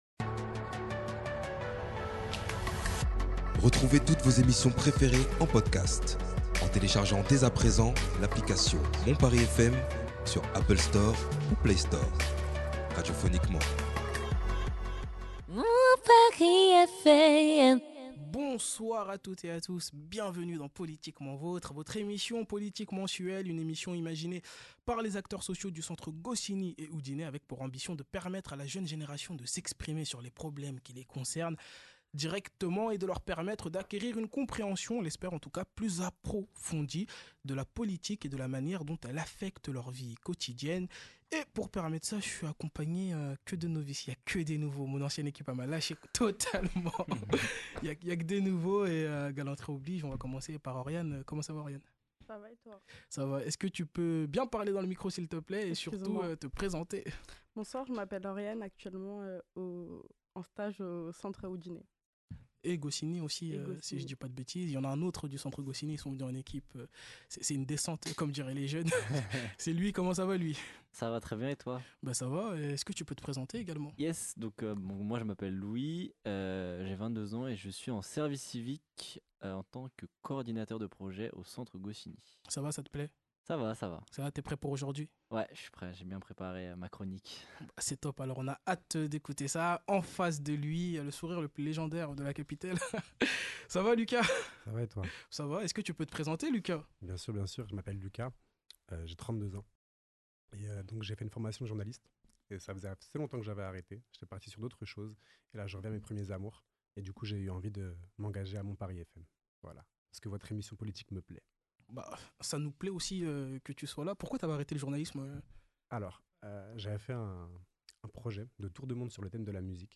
Aujourd'hui dans Politiquement Vôtre, nous avons l'honneur d'accueillir Kevin Havet, une figure emblématique de la vie politique parisienne, particulièrement dans le 18e arrondissement.